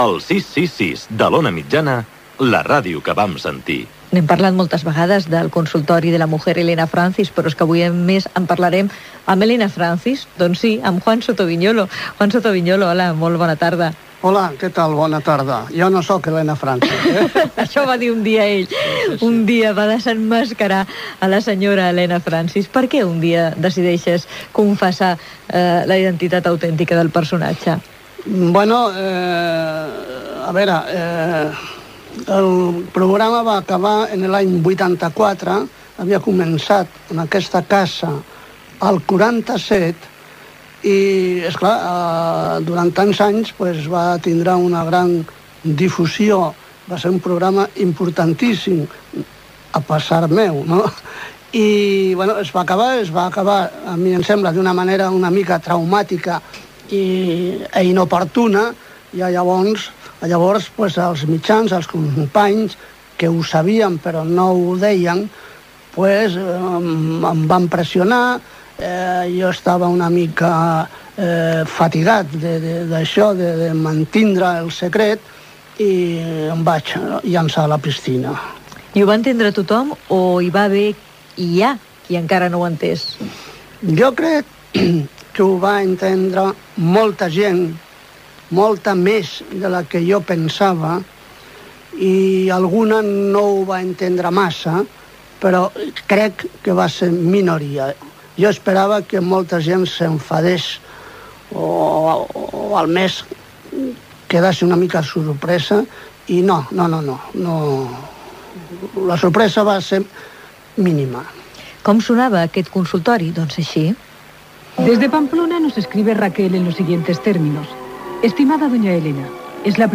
Indicatiu de l'emissora.